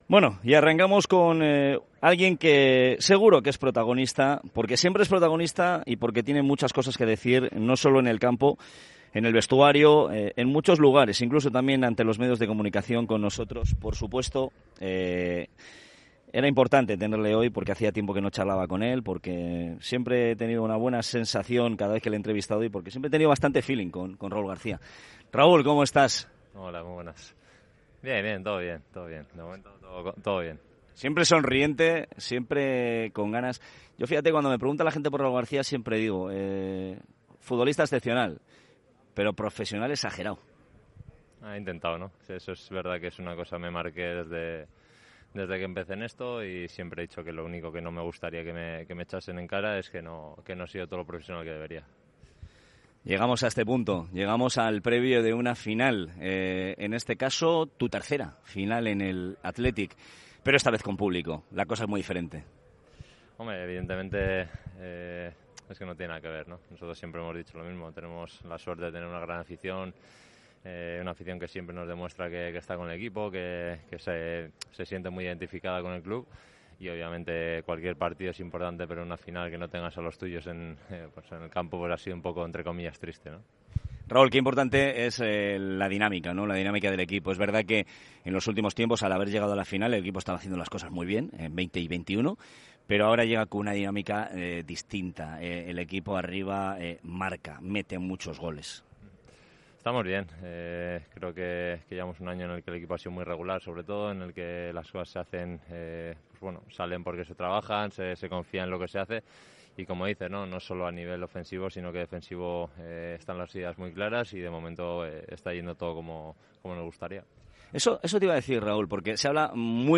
El técnico del Mallorca Javier Aguirre ha comparecido en rueda de prensa en La Cartuja para analizar la final de Copa que enfrentará a los bermellones con el...